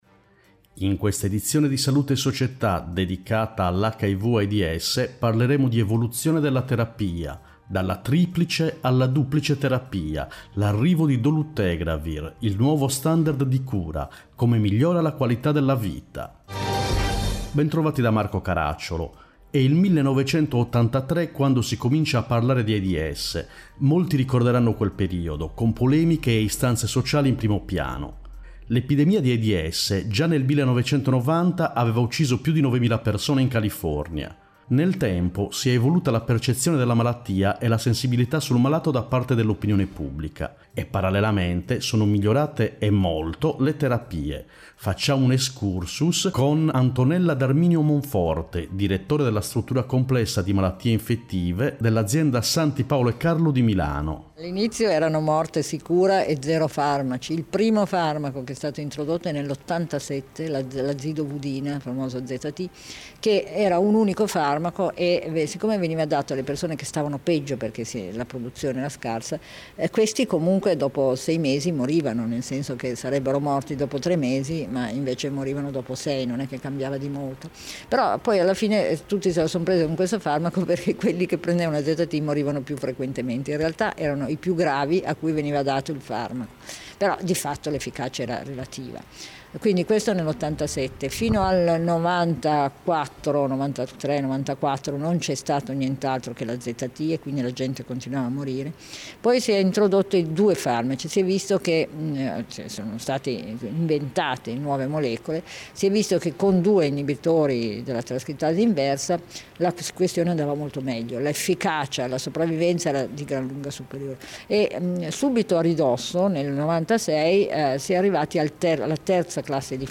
In questa edizione: 1. HIV/Aids, Evoluzione della terapia 2. HIV/Aids, Dalla triplice alla duplice terapia 3. HIV/Aids, L’arrivo di dolutegravir 4. HIV/Aids, Nuovo standard di cura 5. HIV/Aids, Come migliora la qualità della vita Interviste